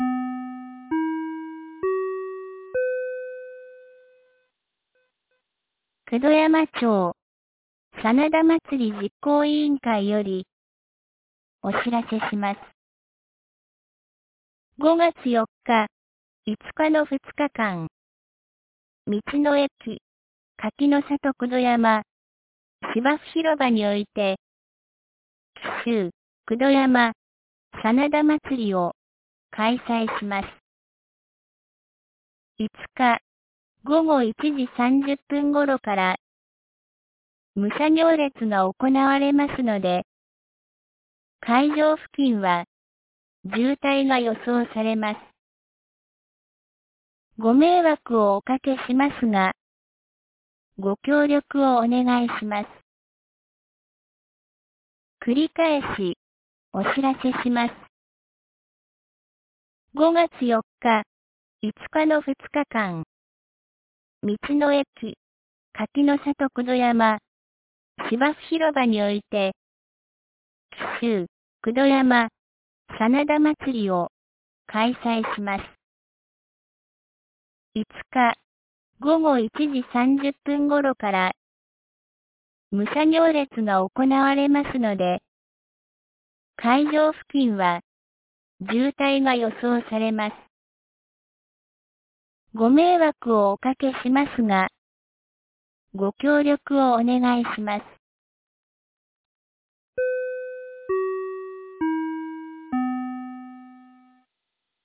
2024年05月01日 12時11分に、九度山町より全地区へ放送がありました。